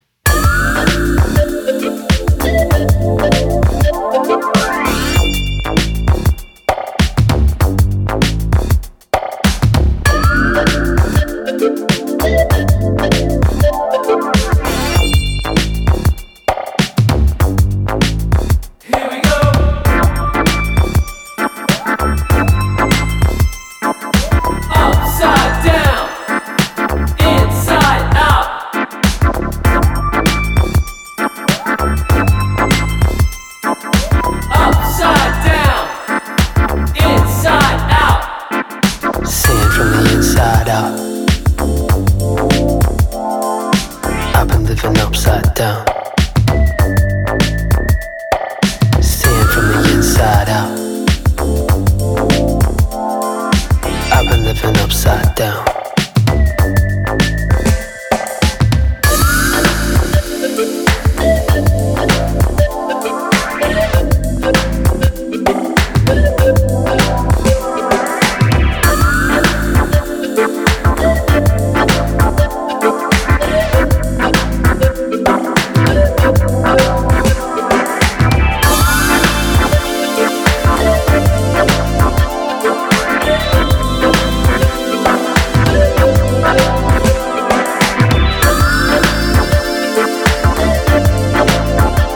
80s drum machine bossa nova